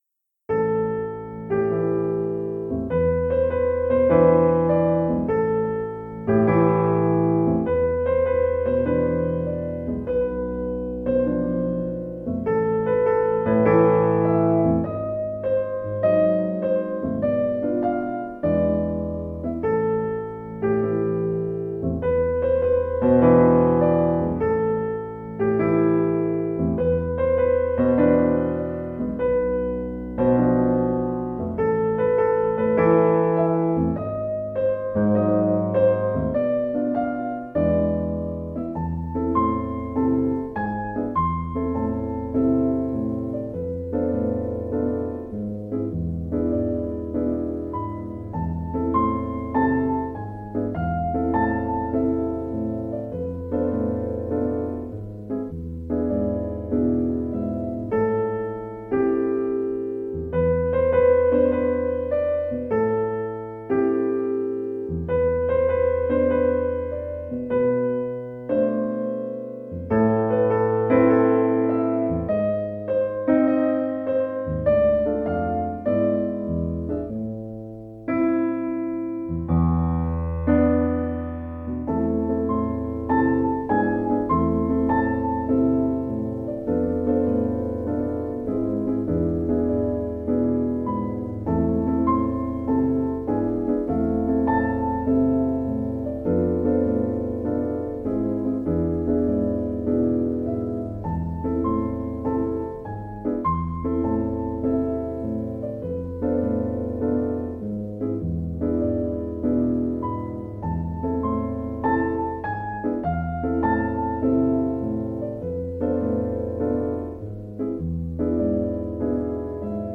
Contains a positive uplifting silent affirmation.